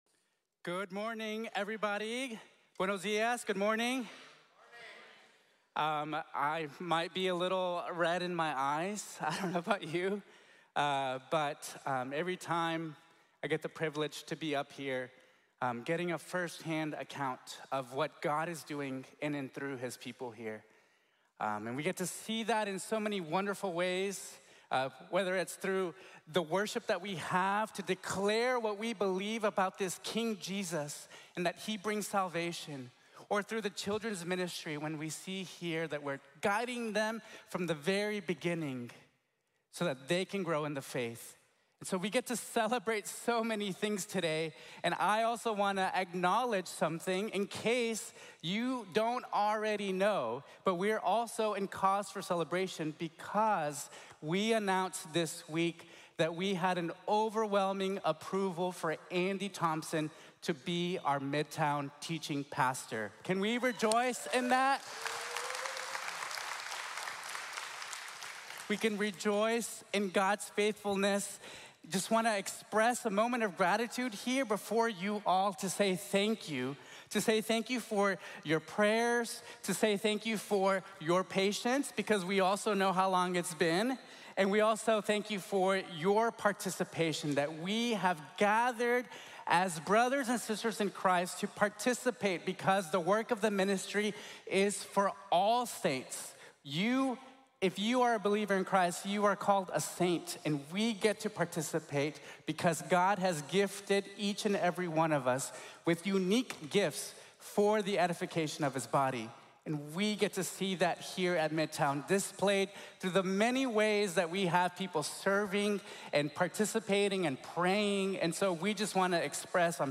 The King Rides Towards His Mission | Sermon | Grace Bible Church